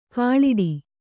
♪ kāḷiḍi